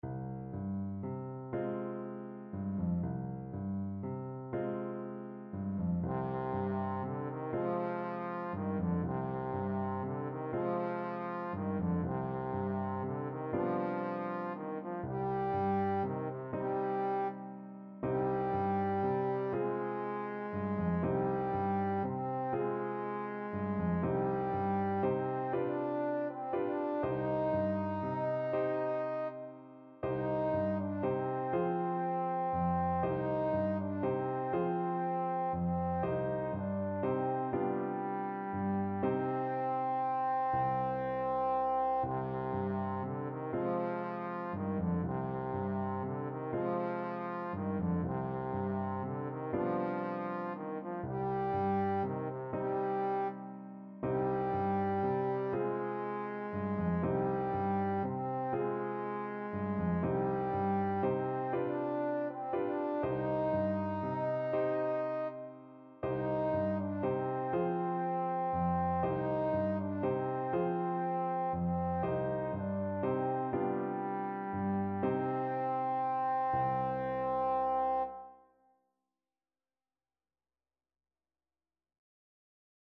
Trombone
6/8 (View more 6/8 Music)
C minor (Sounding Pitch) (View more C minor Music for Trombone )
Gently rocking .=c.40
Traditional (View more Traditional Trombone Music)